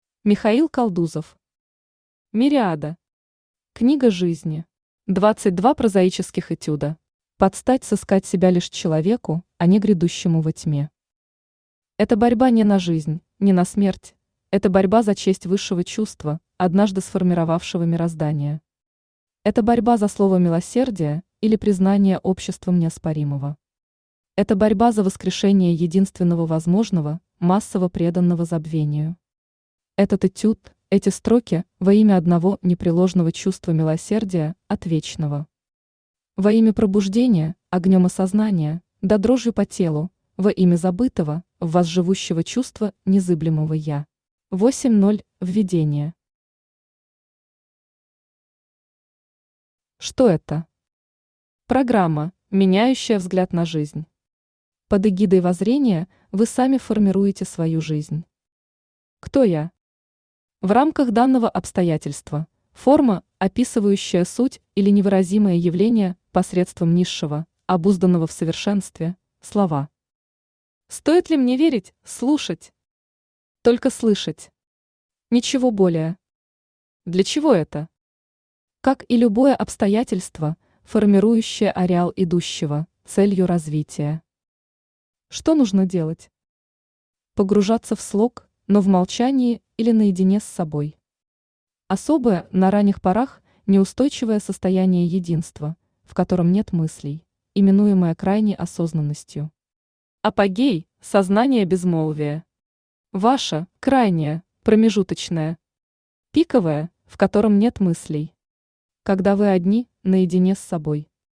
Аудиокнига Мириада. Том 8. 22 прозаических этюда | Библиотека аудиокниг
Читает аудиокнигу Авточтец ЛитРес.